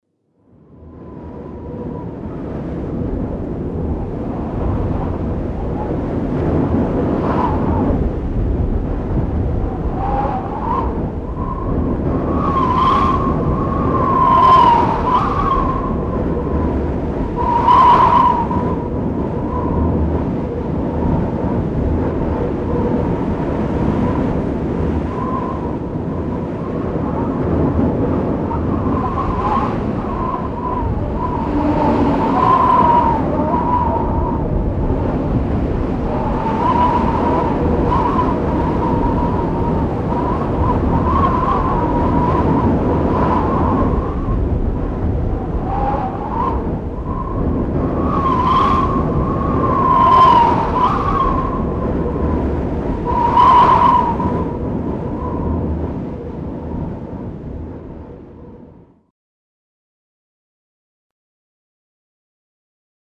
Звук ветра, гуляющего над ледяным заливом
zavyvaniya-vetra-meteli-vyugi.mp3